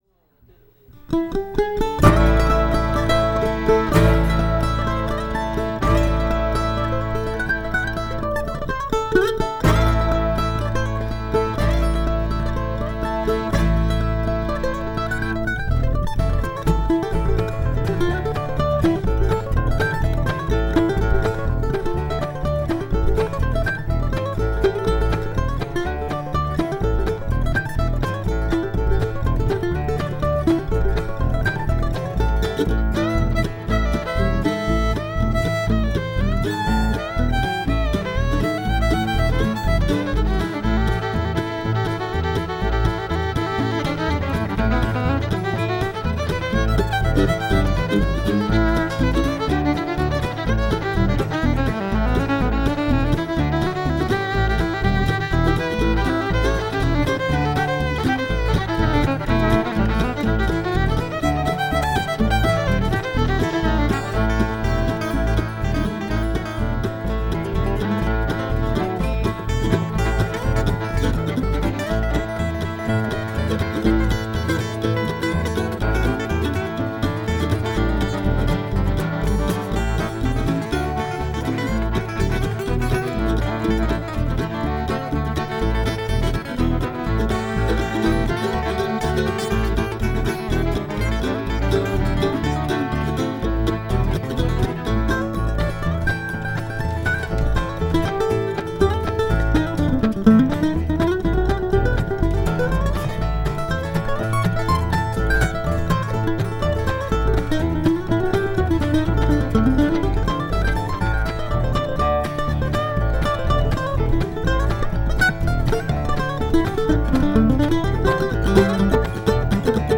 Here’s the first half of the first set.